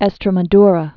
(ĕstrə-mə-drə, ĕsträ-mä-thrä)